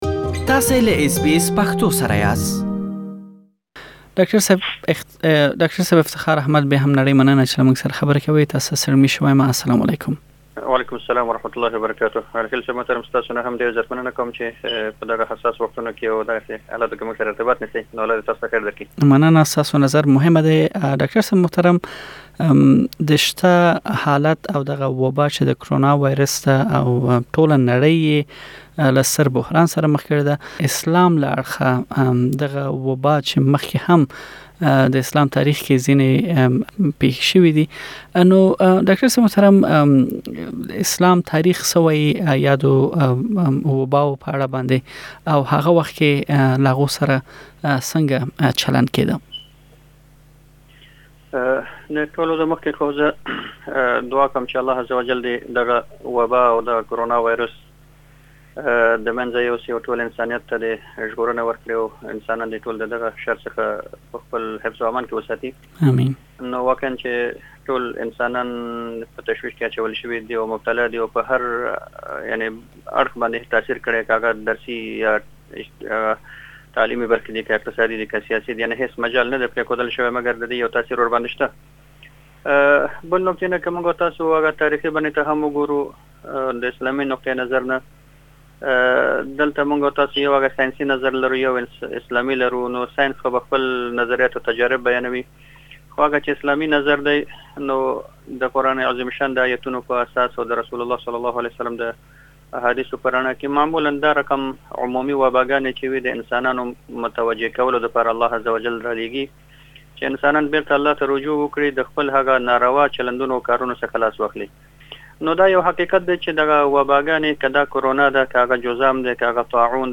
دا او نور مهم مسايل مو د اسلام رڼا کې روښانه کړي چې تاسې لا ډير معلومات مرکه کې واورئ.